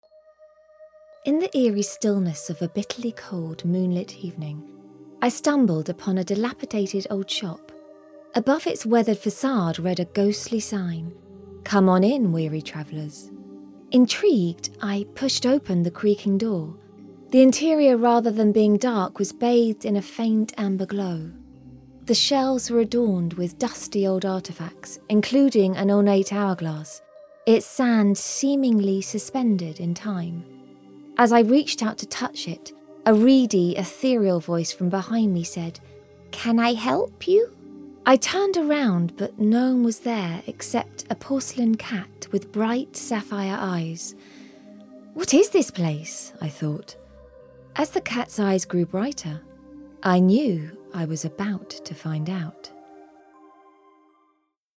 A lively British female voice
Audiobook
British general
Middle Aged
Evocative storytelling for audiobooks.